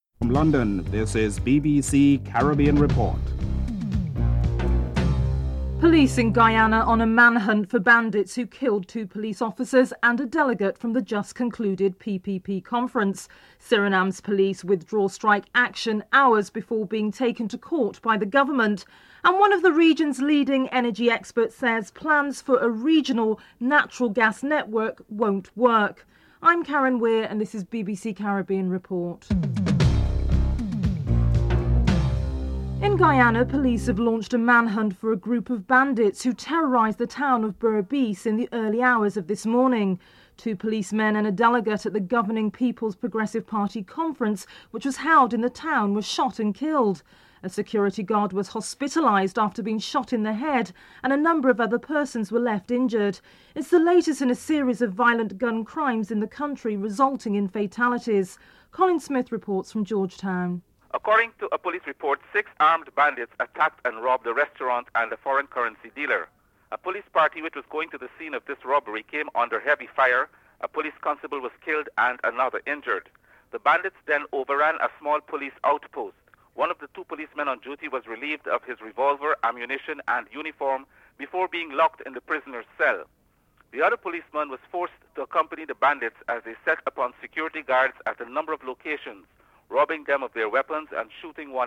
dc.formatStereo 192 bit rate MP3;44,100 Mega bits;16 biten_US
dc.typeRecording, oralen_US